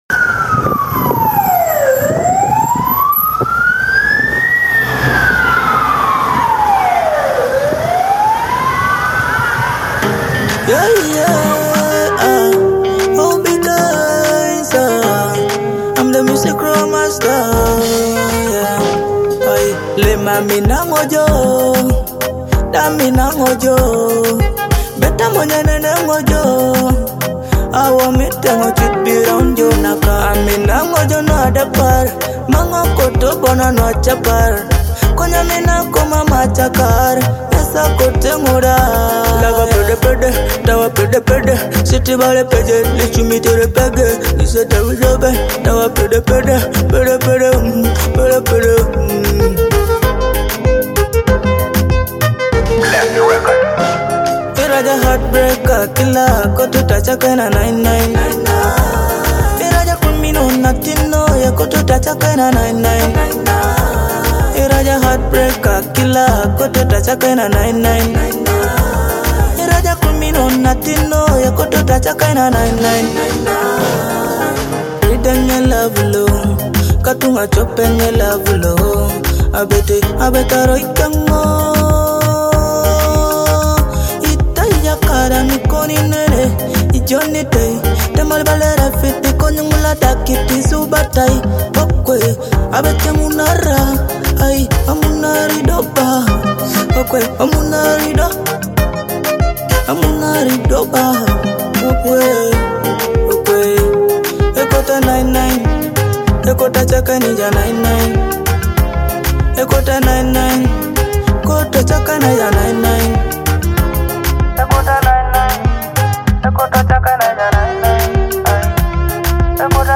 an emotional Teso love song
Through a soulful delivery and relatable storytelling